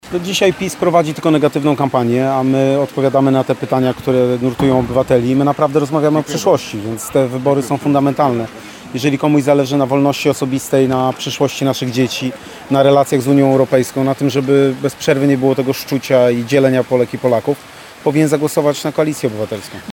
Bielsko-Biała: na Pl. Wojska Polskiego odbyło się spotkanie z prezydentem Warszawy i wiceprzewodniczącym PO – Rafałem Trzaskowskim.